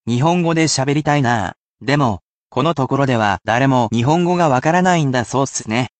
I can only read it at one speed, so there is no need to repeat after me, but it can still assist you in picking out vocabulary within natural speeds of speech.
nihongo de shaberitai naa. demo, kono tokoro de wa, daremo nihongo ga wakaranai nda sou ssune.